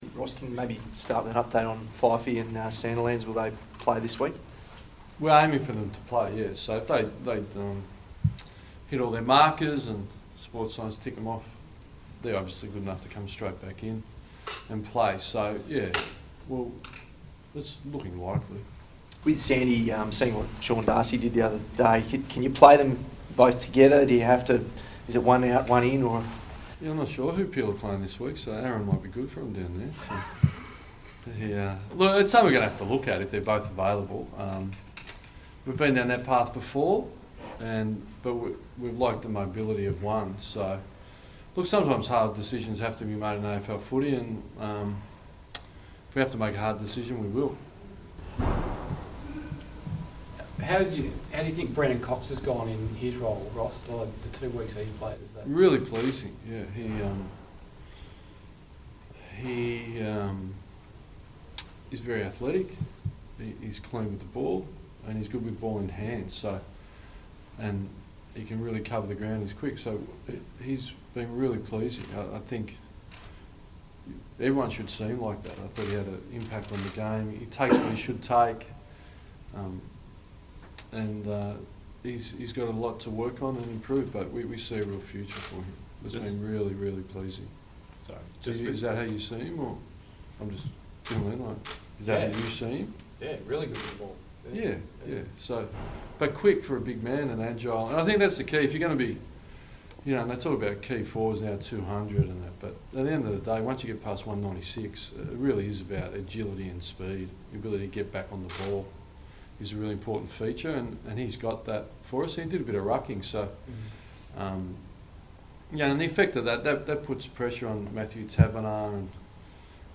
Ross Lyon press conference - 28 June